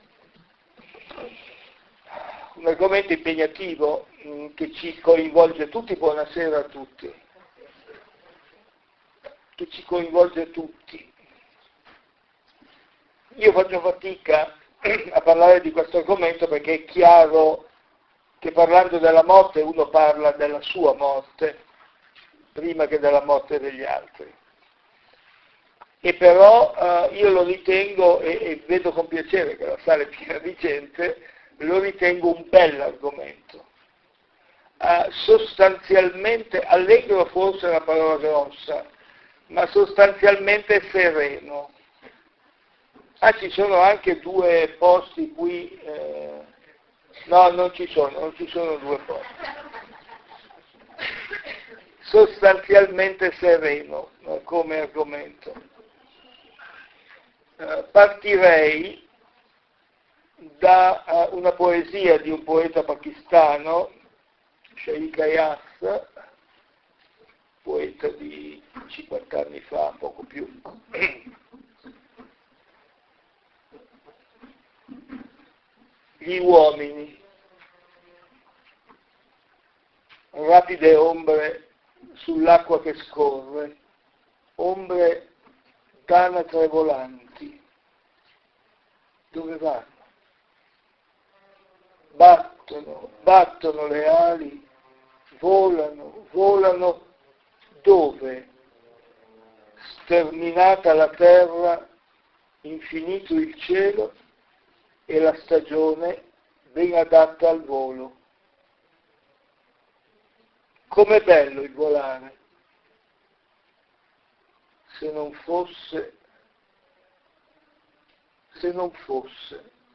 Conferenze sulla morte, tenute al Teatro Civico di Oleggio (NO) il 6 e il 13 marzo 2014.